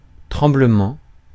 Ääntäminen
IPA: [tʁɑ̃.blǝ.mɑ̃]